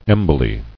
[em·bo·ly]